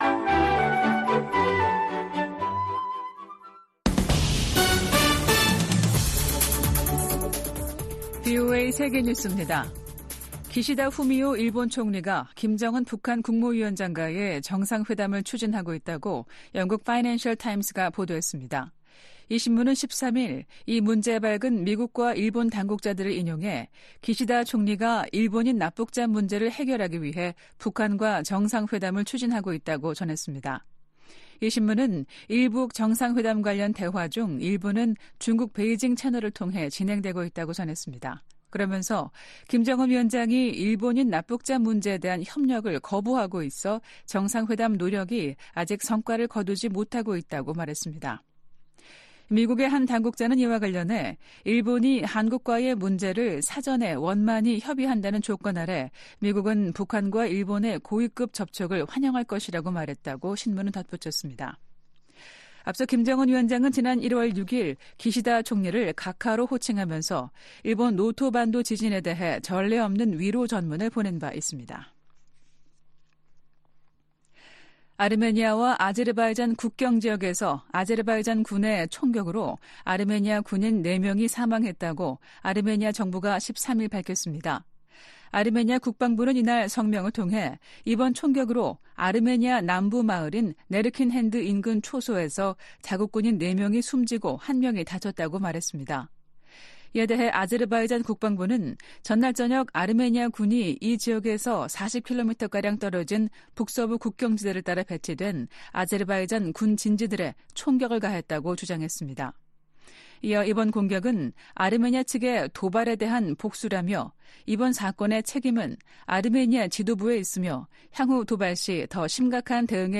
VOA 한국어 아침 뉴스 프로그램 '워싱턴 뉴스 광장' 2024년 2월 14일 방송입니다. 존 커비 백악관 국가안전보장회의(NSC) 전략소통조정관은 인도태평양 전략 발표 2주년을 맞아 대북 감시를 위한 한반도 주변 역량 강화가 큰 성과라고 평가했습니다. 러시아가 북한의 7차 핵실험 가능성 등을 언급하며 북한 입장을 두둔하고 있습니다. 로버트 켑키 미 국무부 부차관보는 미국·한국·일본이 북한-러시아 군사협력 대응 공조에 전념하고 있다고 밝혔습니다.